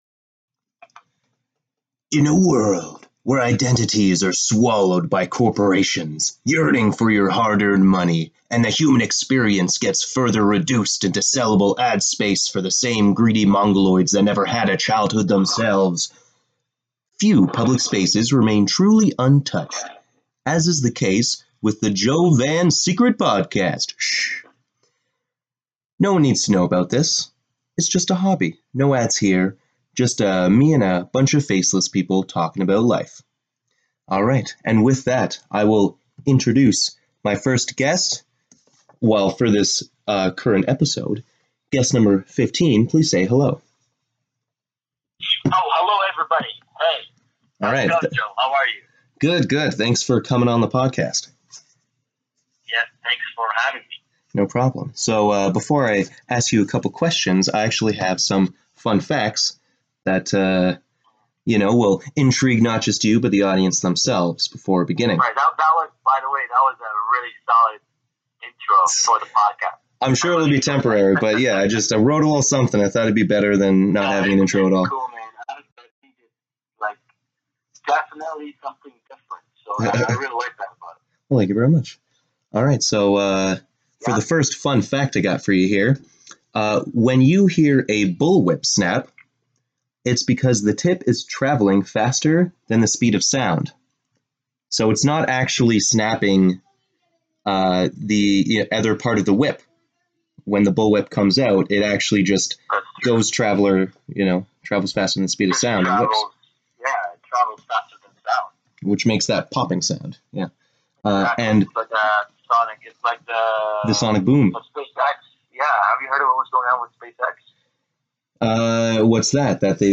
In this episode I interview the CEO of a startup company.